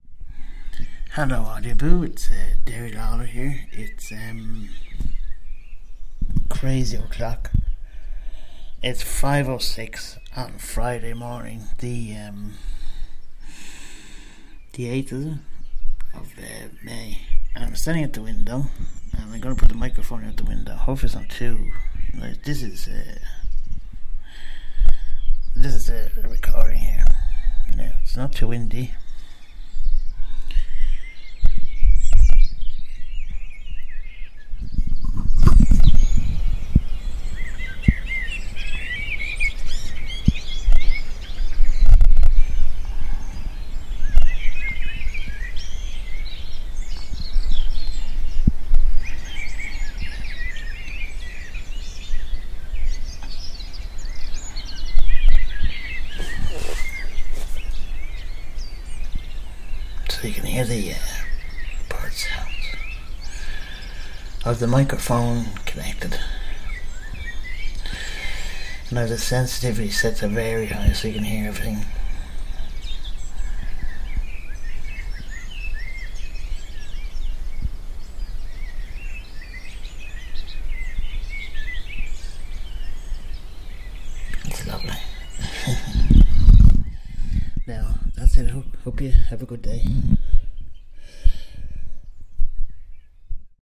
dawn chorus